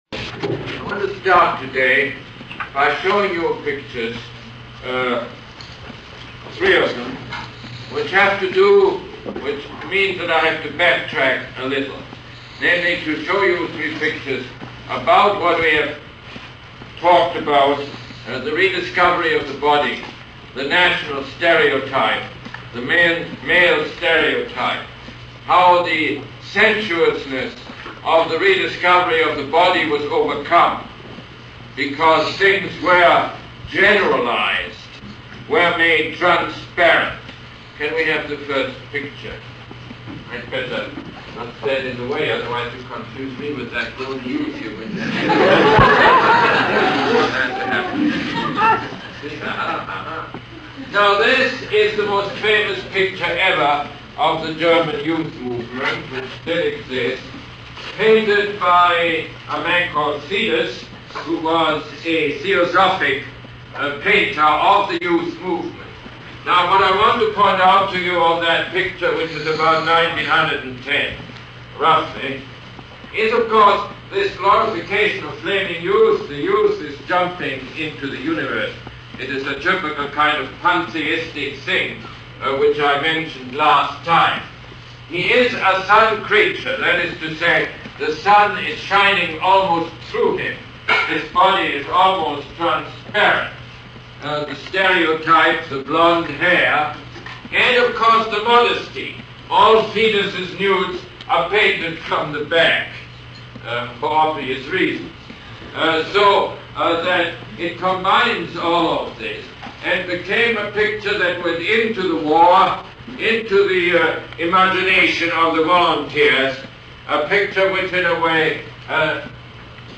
Lecture #19 - November 14, 1979